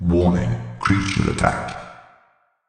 CyclopsCreatureAttack.ogg